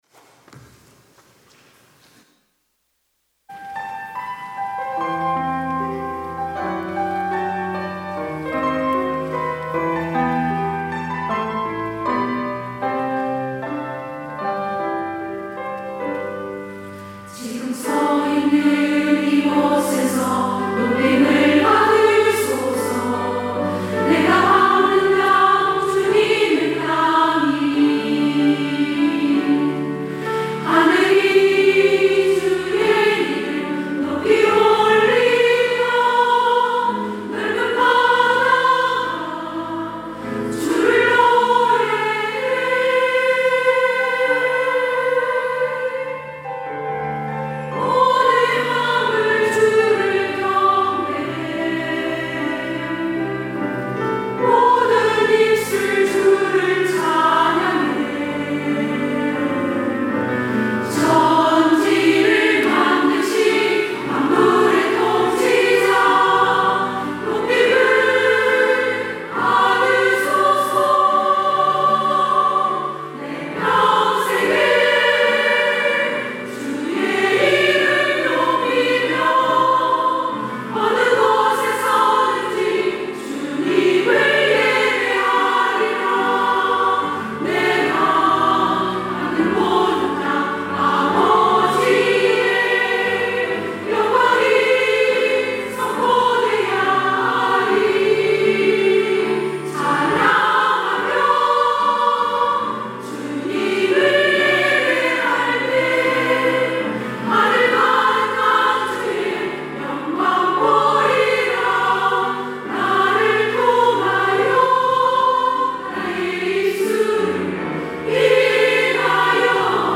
여전도회 - 나를 통하여